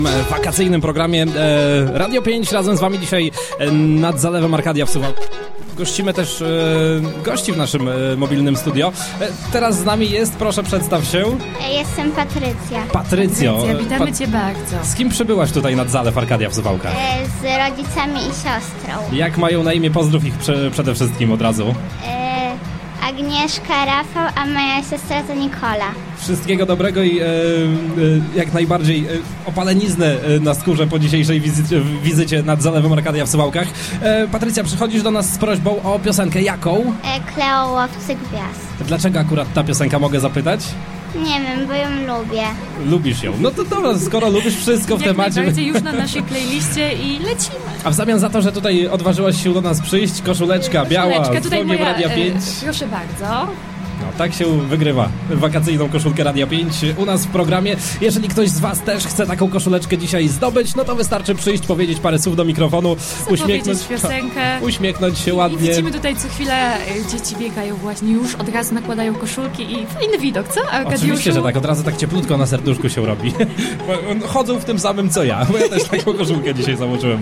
Na pierwszy cel obrali plażę miejską nad zalewem Arkadia w Suwałkach. Mobilne studio RADIA 5 odwiedziło wielu gości.